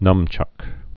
(nŭmchŭk)